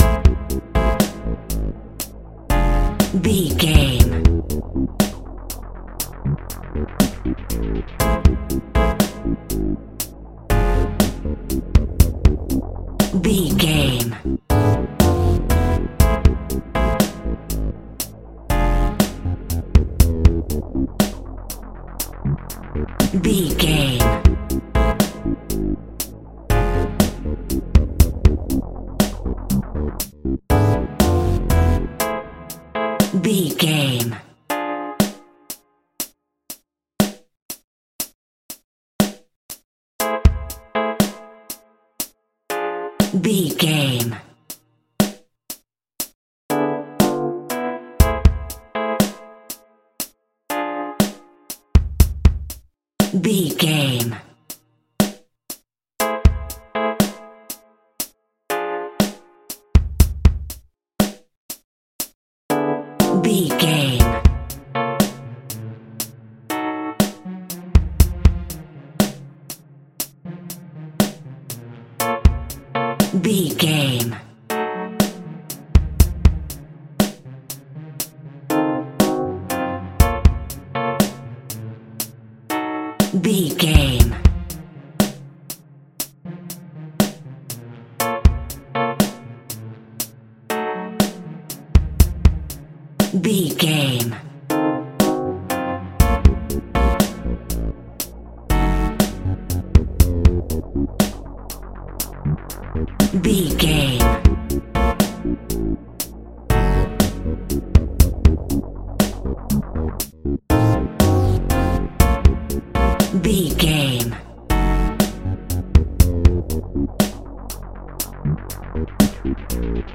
Aeolian/Minor
tension
ominous
dark
suspense
eerie
synthesizer
Synth Pads
atmospheres